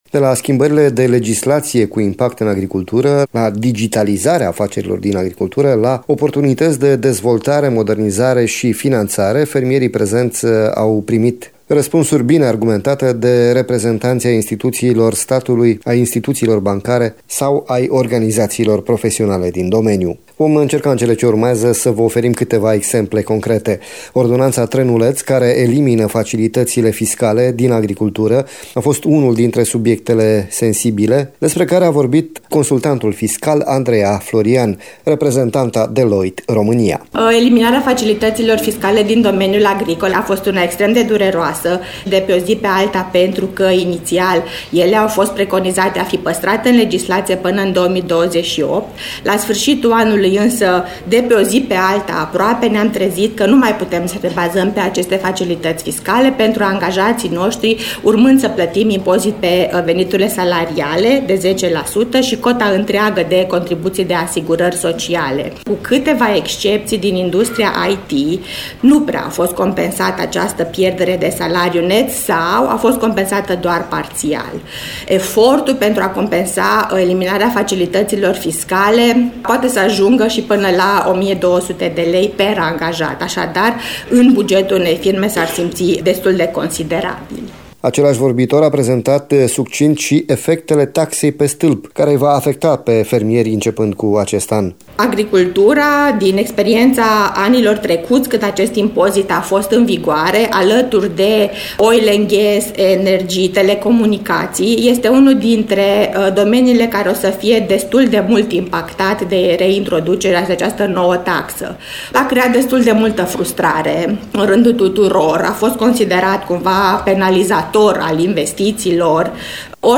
Conferința Agrovest, direcții sustenabile pentru fermieri, a fost găzduită de Palatul Administrativ din Timișoara și a fost organizată de Asociația Produrabilă în parteneriat cu Consiliul Județean Timiș.